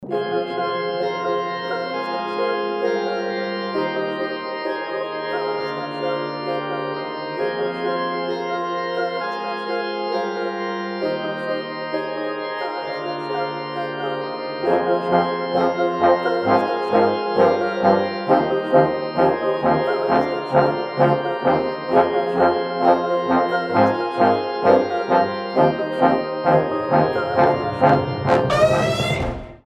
• Качество: 320, Stereo
Electronic
без слов
инструментальные
тревожные
варган